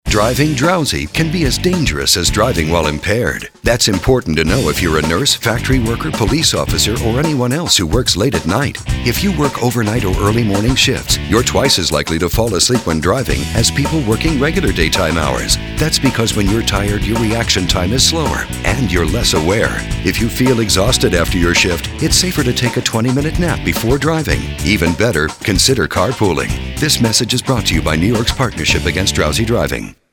Shift Workers :30 Radio PSA.